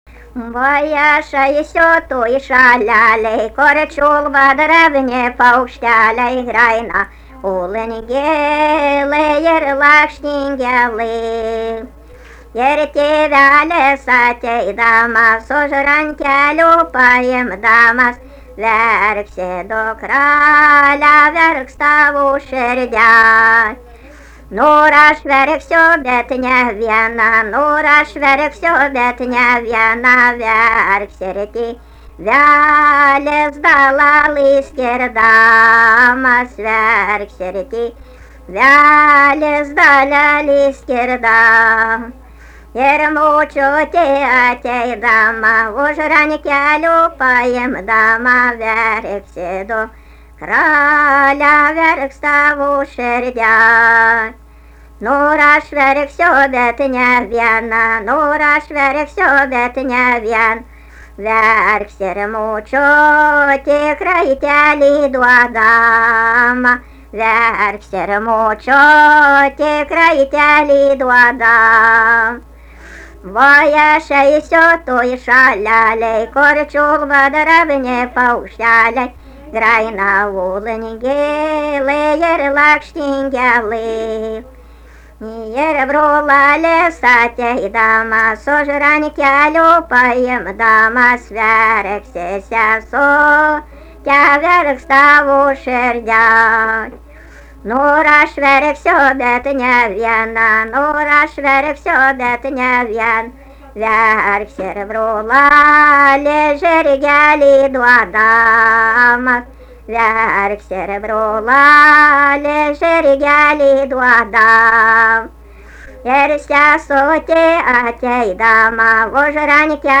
daina
Pauosupė
vokalinis